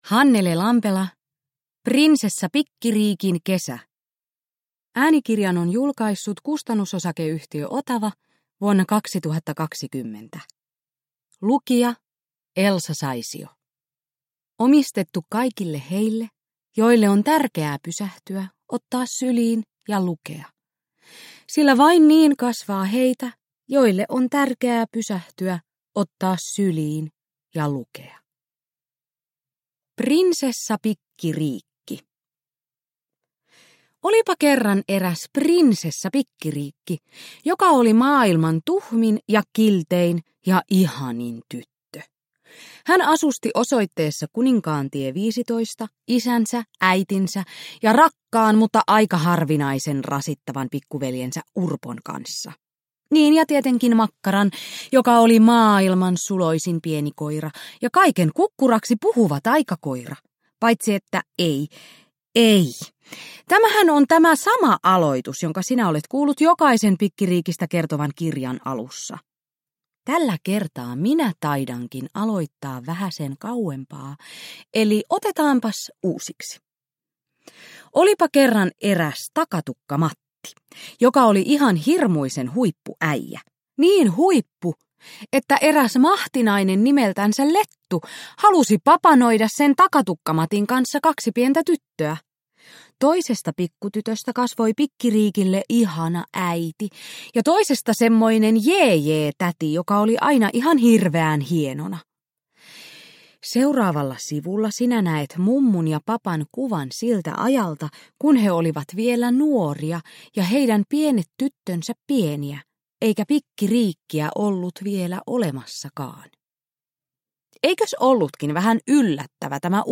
Prinsessa Pikkiriikin kesä – Ljudbok – Laddas ner
Uppläsare: Elsa Saisio